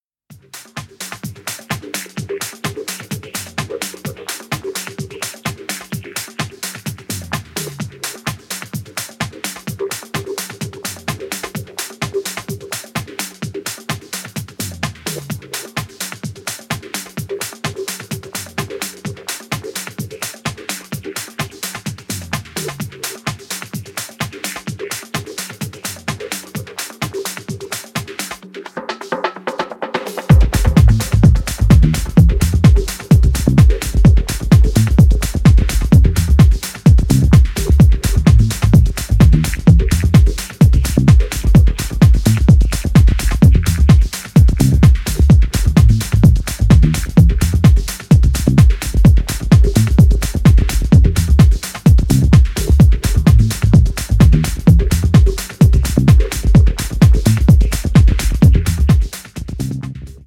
Three Groovy Cuts